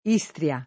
Istria [ &S tr L a ]